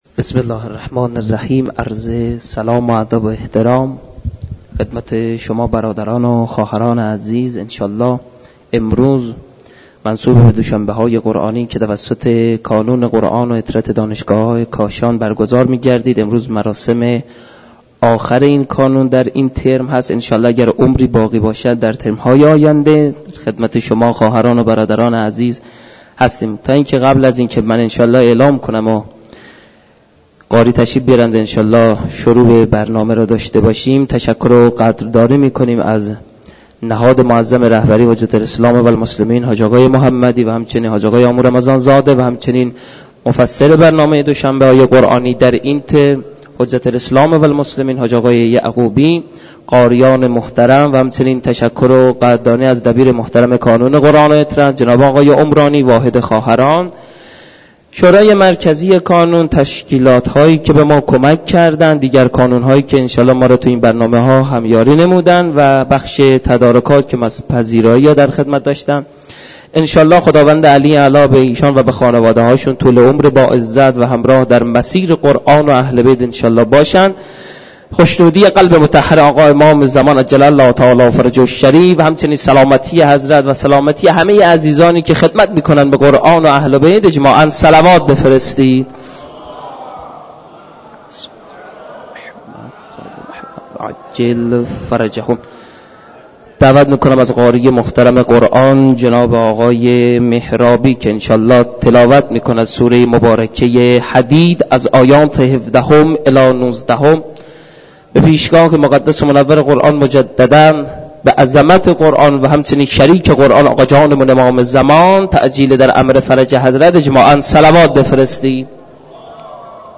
برگزاری مراسم معنوی دوشنبه های قرآنی در مسجد دانشگاه کاشان